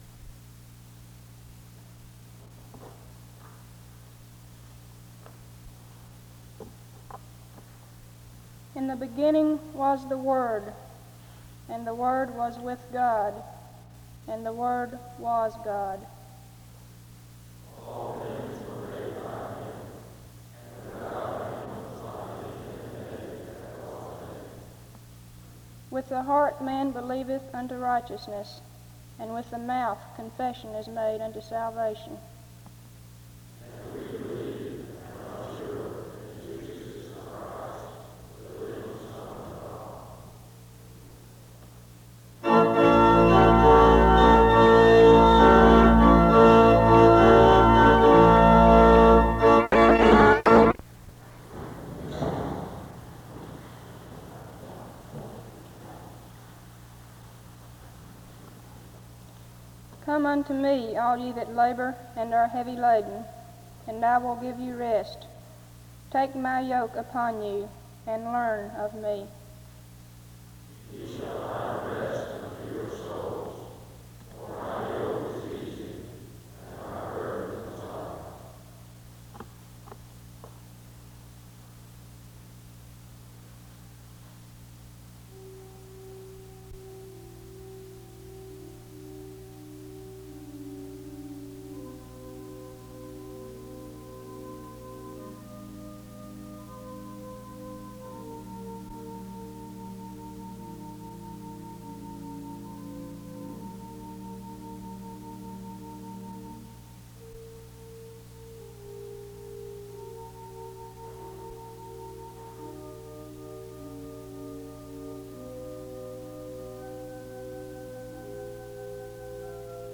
Download .mp3 Description The service opens with a responsive reading (00:00-01:19) followed by instrumental music and congregational worship (01:20-07:03).
A solo occurs after this Scripture reading, (12:18-14:56) followed by another responsive reading (15:12-15:58) and instrumental music which is cut short (15:59-16:59). The audio of an unnamed speaker suddenly begins mid-sentence, talking about what is happening in Africa, including rapid political, social, and religious changes. He emphasizes the timeliness of missions in Africa while these changes and happening (17:00-33:02).